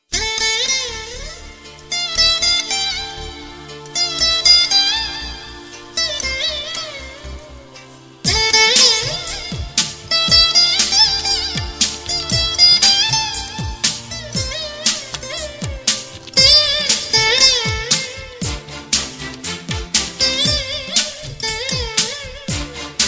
Category: Sad Ringtones